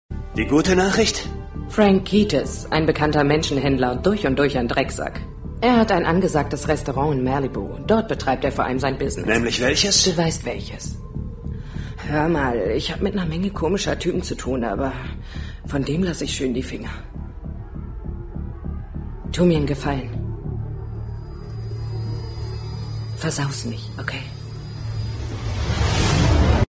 Sprachproben
Sprecherin, Synchronsprecherin, Schauspielerin, Sängerin